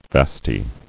[vast·y]